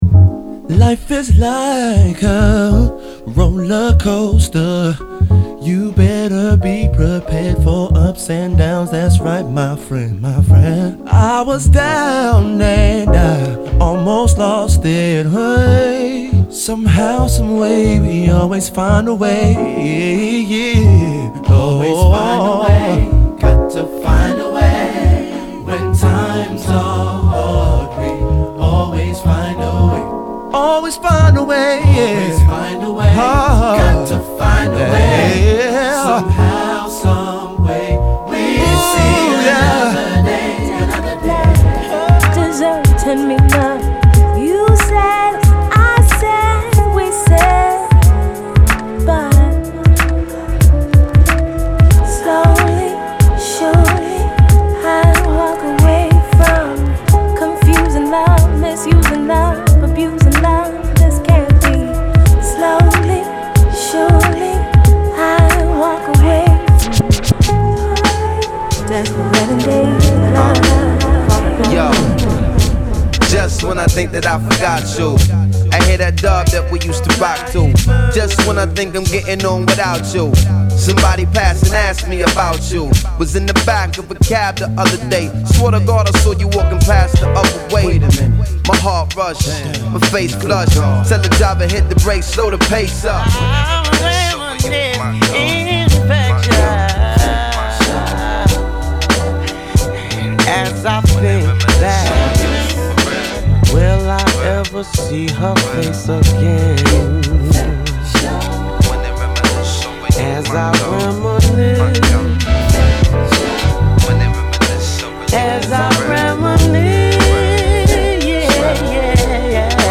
今作は新旧のR&B、Nu Soulで構成。
＊試聴はダイジェストです。